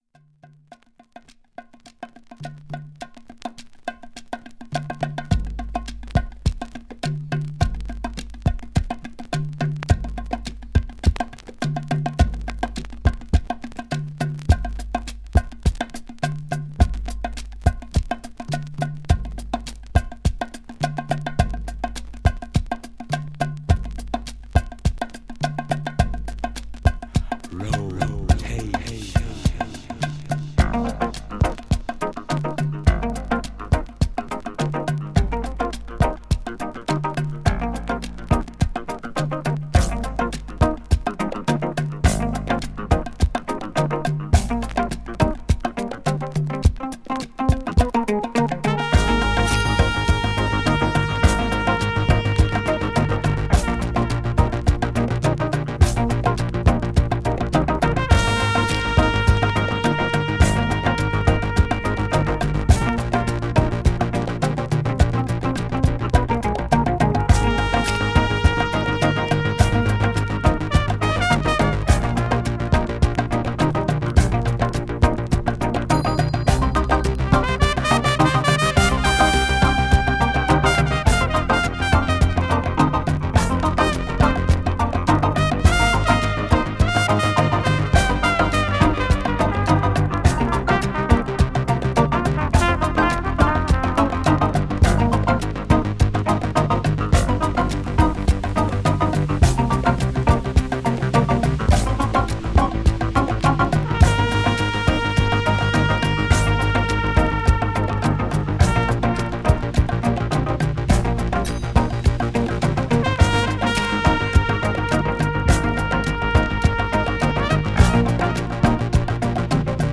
JAZZFUNK
JAZZ FUNK人気盤！！！
チリチリあります。